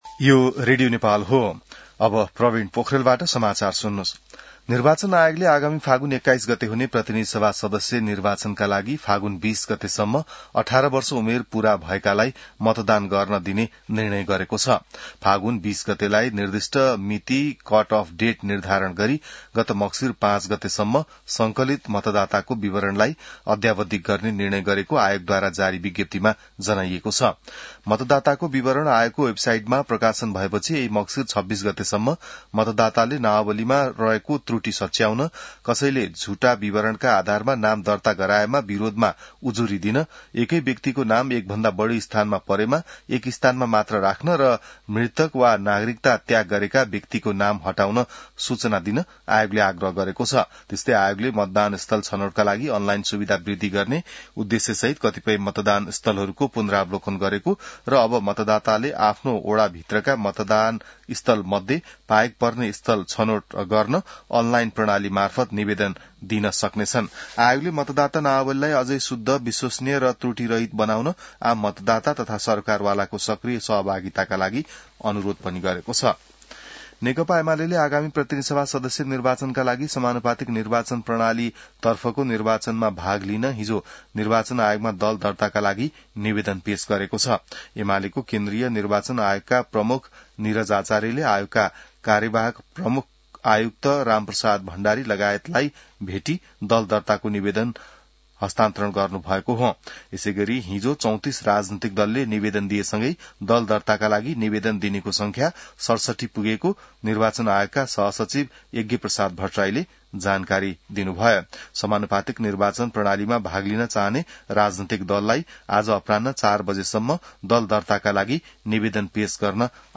An online outlet of Nepal's national radio broadcaster
बिहान ६ बजेको नेपाली समाचार : २३ मंसिर , २०८२